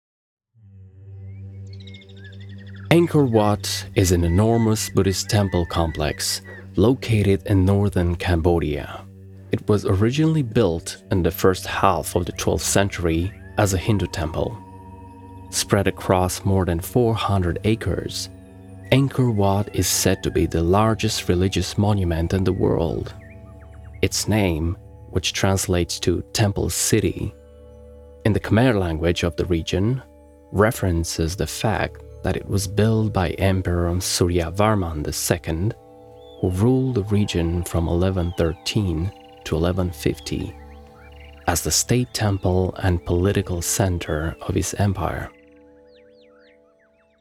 Factual Narration (American accent)